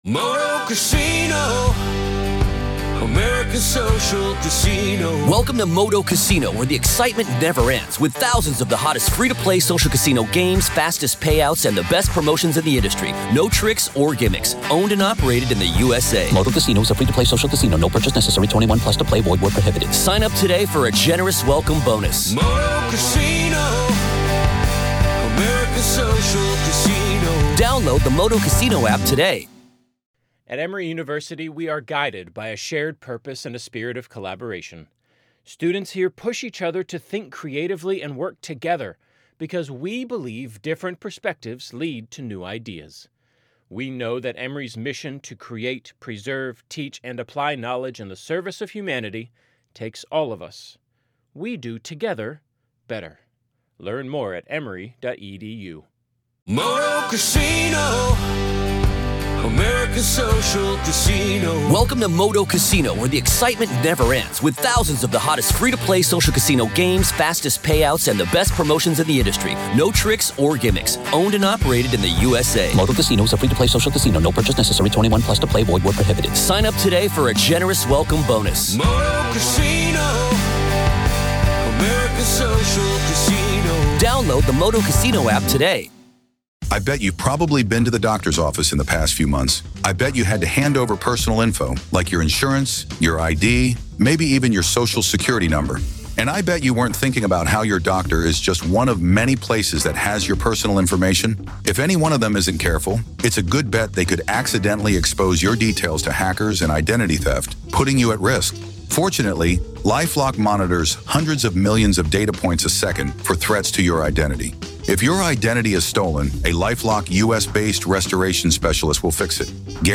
In a gripping segment , listeners were presented with a rare and compelling piece of audio from the courtroom during the high-profile trial of Alex Murdaugh.
This revelation sparked a discussion on the podcast about the implications of such influences on the integrity of the verdict and the broader judicial process. The hosts and legal experts weighed in, dissecting the nuances of jury deliberations and the power of witness testimonies in high-stakes trials.